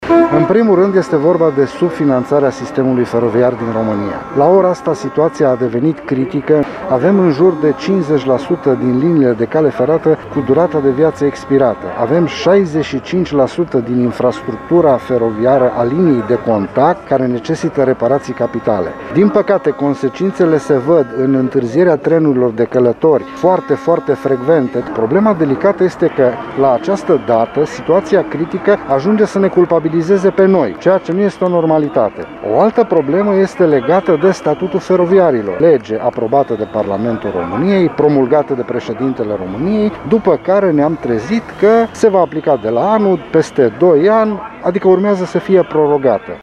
Sindicatele angajaților de Căi Ferate din Timișoara au protestat astăzi în fața Prefecturii Timiș.